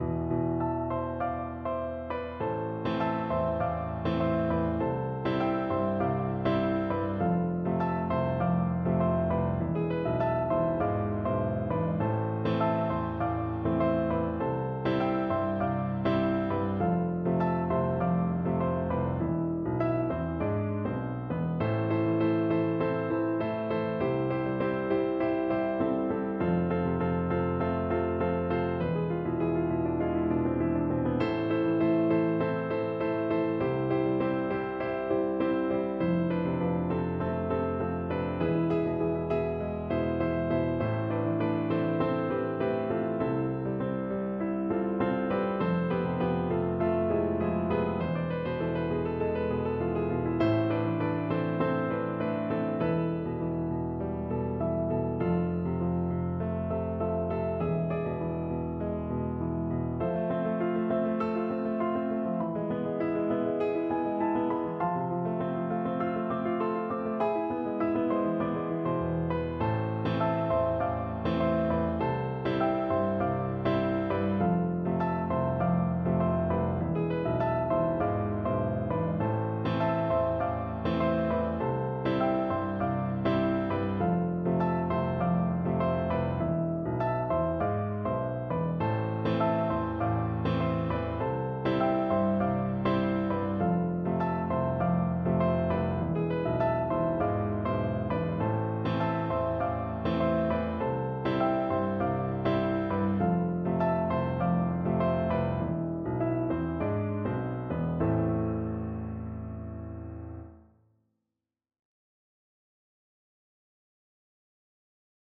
Ноты для фортепиано.
*.mid - МИДИ-файл для прослушивания нот.